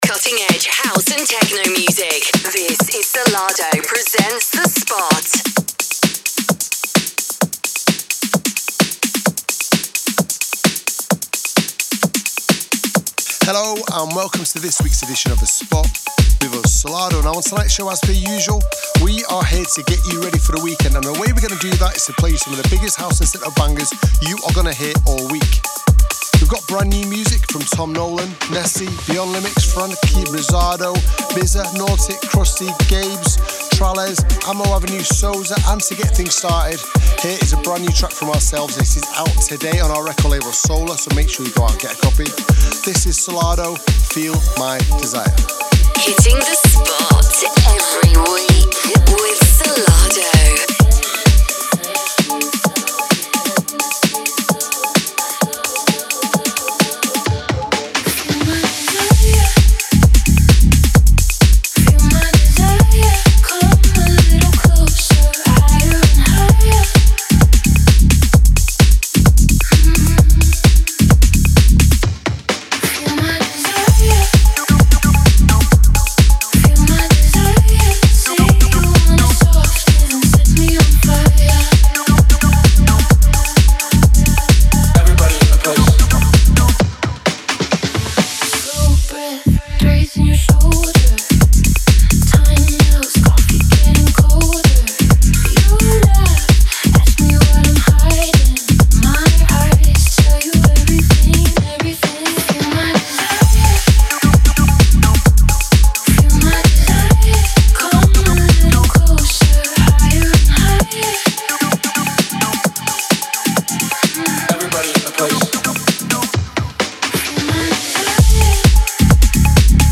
music DJ Mix in MP3 format
Genre: Tech House